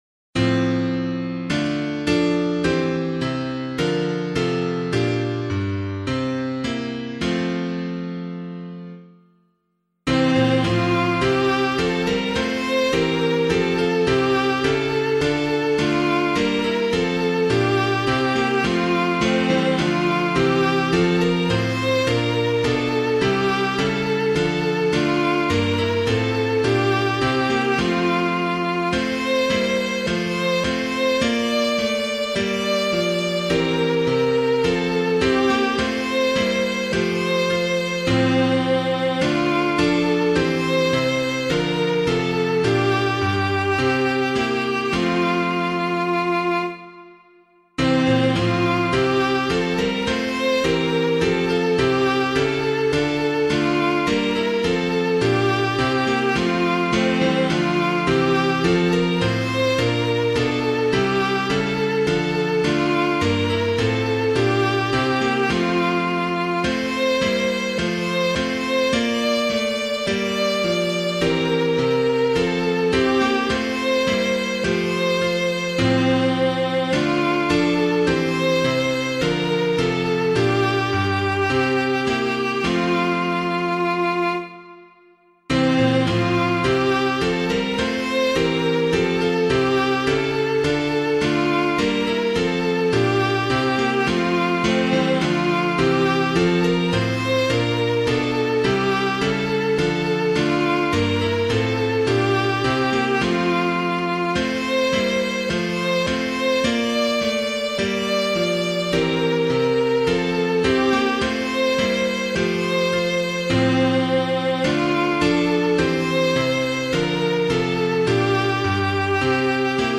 Hymn suitable for Catholic liturgy
To Jesus Christ Our Sovereign King [Hellriegel - ICH GLAUB AN GOTT] - piano.mp3